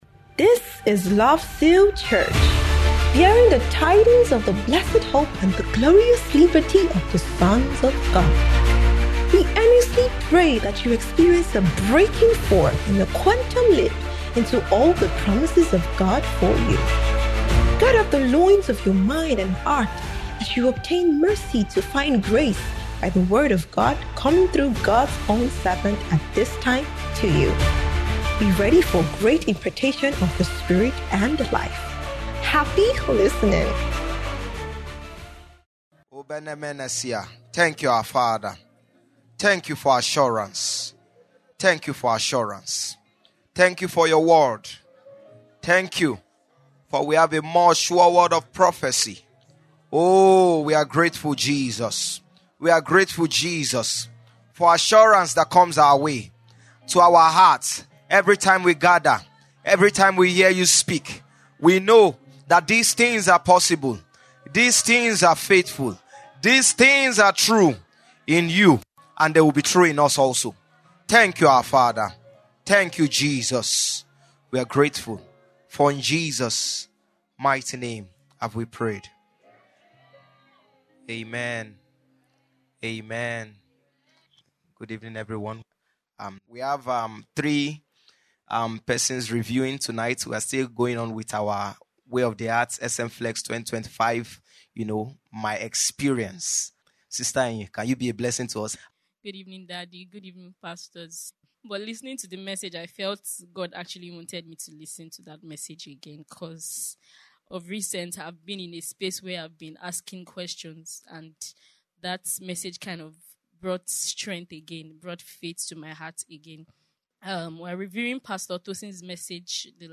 WOTHSMFLX'25 Review VIII & Special Teaching